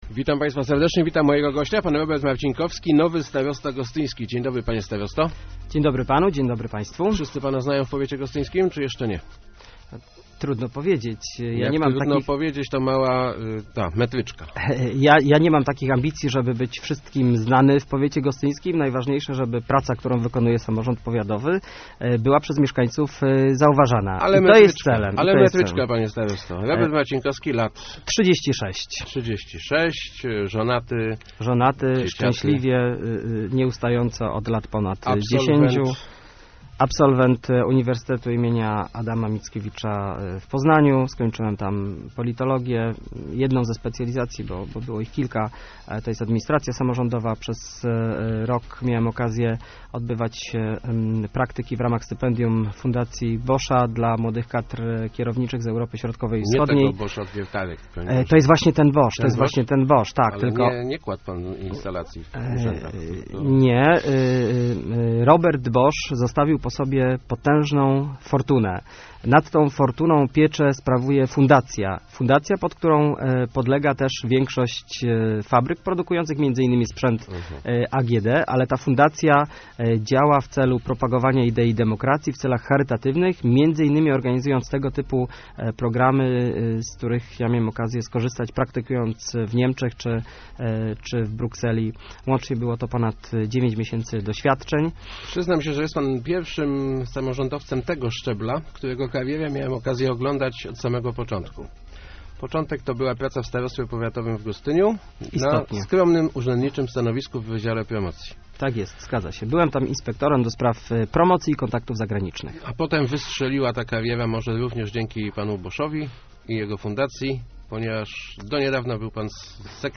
Pomysł, żebym został starostą wyszedł kilka miesięcy temu od mojego poprzednika Andrzeja Pospieszyńskiego - mówił w Rozmowach Elki Robert Marcinkowski, nowy starosta gostyński.